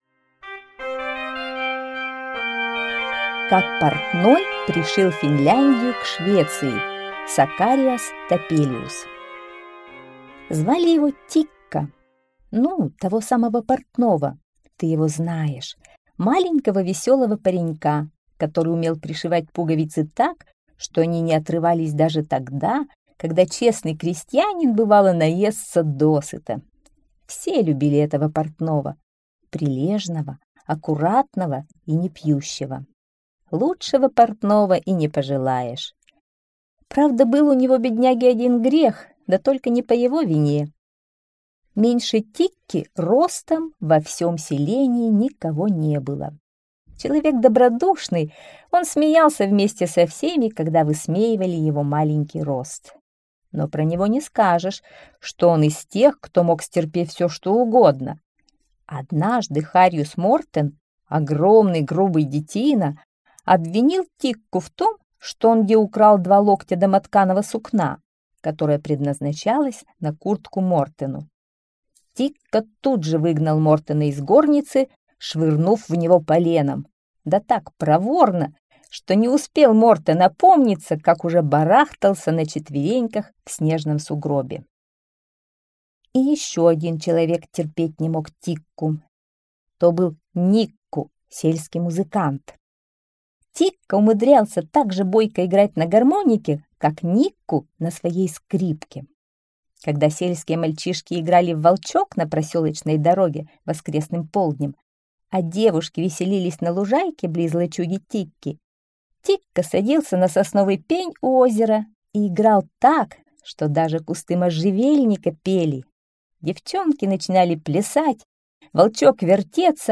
Как портной пришил Финляндию к Швеции - аудиосказка Топелиуса С. Сказка про паренька-портного, которого любила вся деревня.